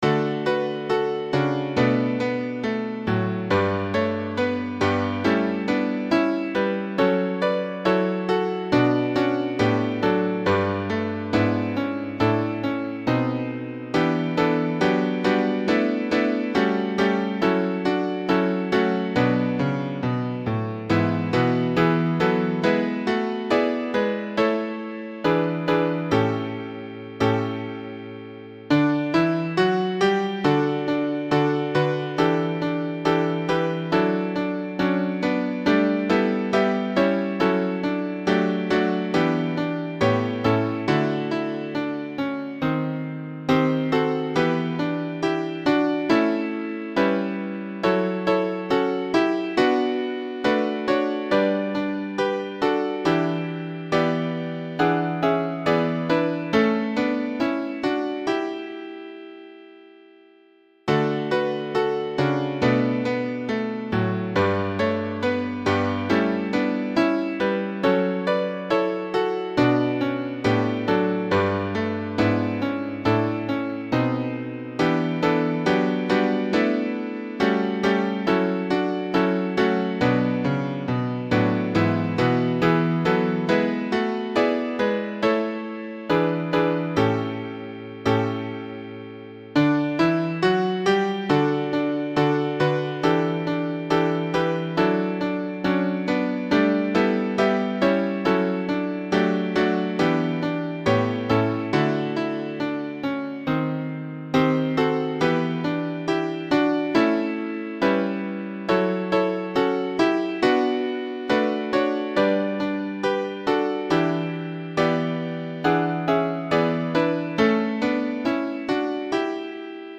Vitesse : tutti Problème avec le tag audio 100 % soprane Problème avec le tag audio 100 % alto Problème avec le tag audio 100 % tenor Problème avec le tag audio 100 % basse Problème avec le tag audio 100 %